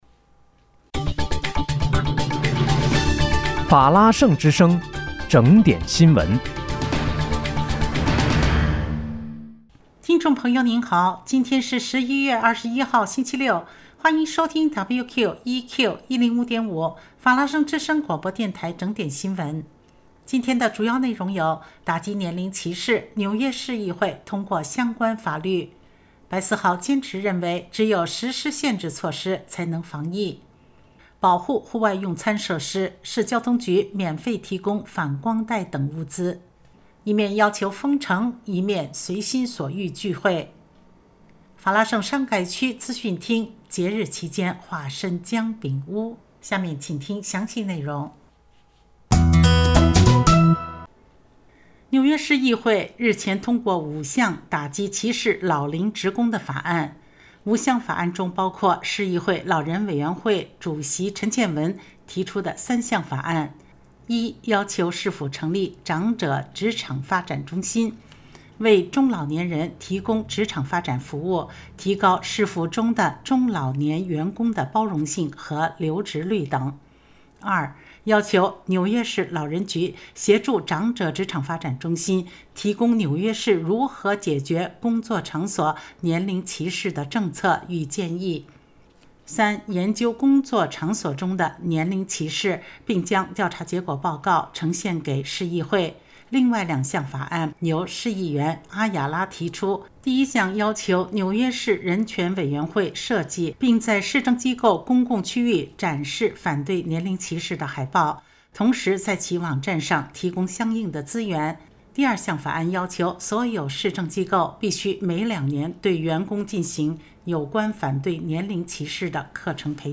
11月21日（星期六）纽约整点新闻